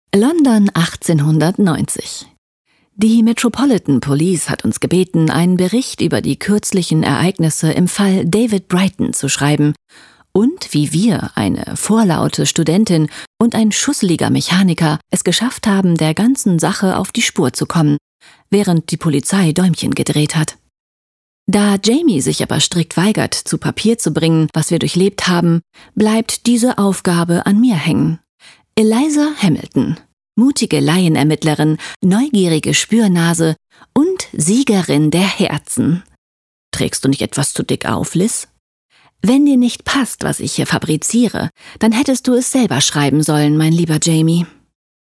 Imagefilm - Dibella longlife textiles